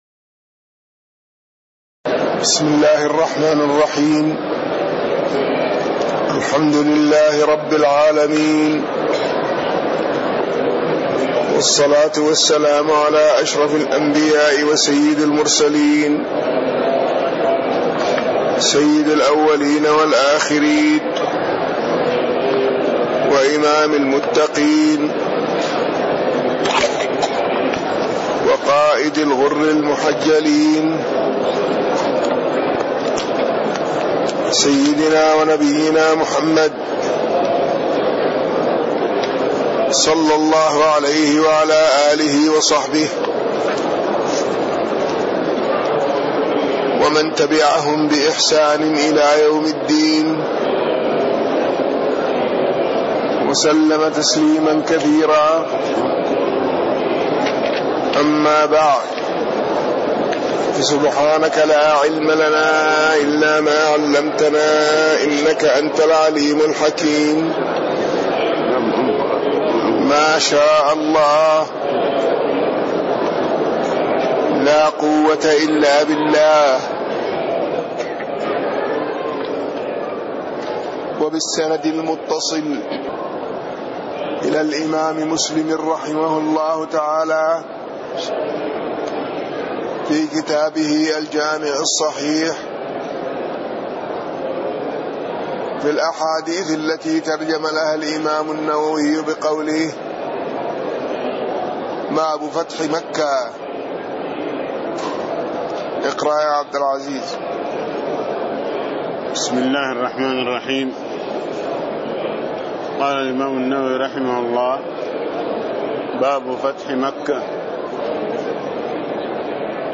تاريخ النشر ٨ ذو القعدة ١٤٣٥ هـ المكان: المسجد النبوي الشيخ